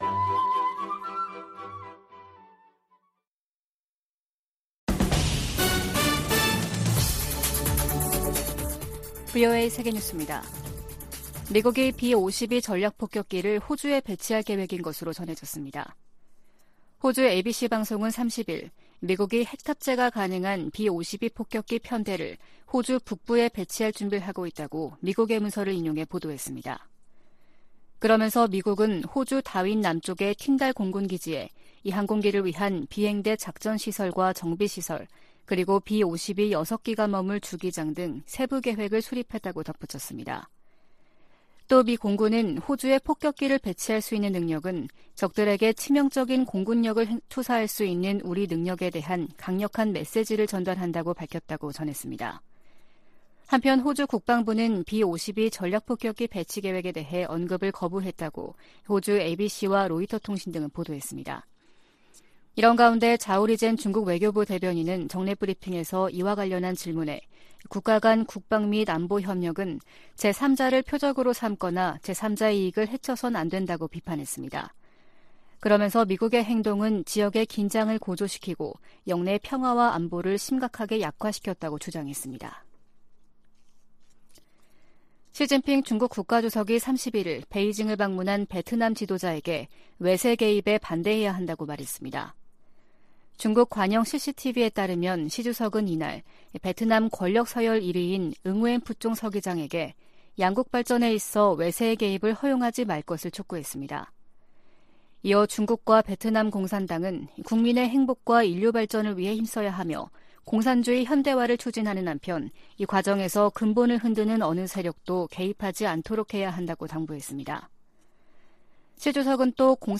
VOA 한국어 아침 뉴스 프로그램 '워싱턴 뉴스 광장' 2022년 11월 1일 방송입니다. 미국 국무부가 ‘한반도의 완전한 비핵화’에 대한 의지를 확인하면서 북한의 대화 복귀를 거듭 촉구했습니다. 미국과 한국의 최신 군용기들이 대거 참여한 가운데 한반도 상공에서 펼쳐지는 연합 공중훈련 비질런스 스톰이 31일 시작됐습니다.